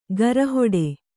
♪ gara hoḍe